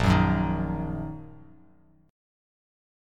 Bbsus4#5 chord